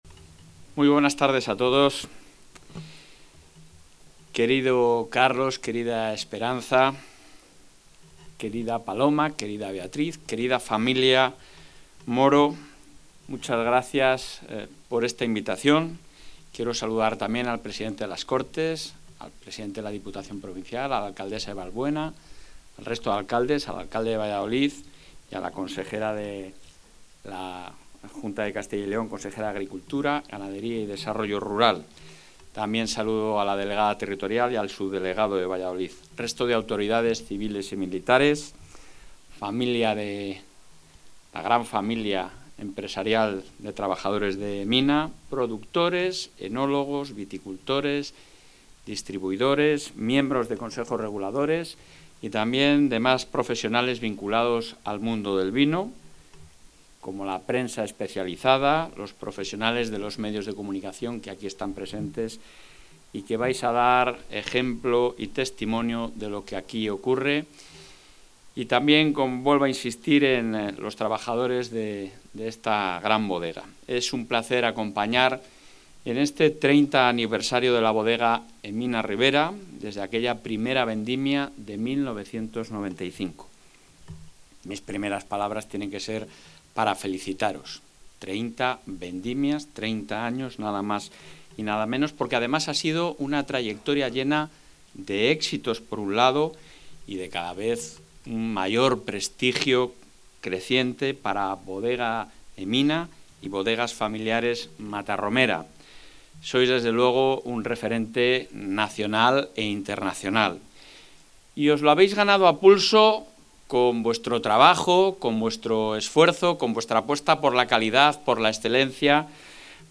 Intervención del presidente de la Junta.
El presidente de la Junta de Castilla y León, Alfonso Fernández Mañueco, ha participado en la localidad vallisoletana de Valbuena de Duero en el acto de celebración del 30 aniversario de la Bodega Emina, donde ha subrayado el papel del sector vitivinícola como motor económico, de innovación y sostenibilidad, y elemento clave en la generación de oportunidades y fijación de población, fundamentalmente, en el medio rural.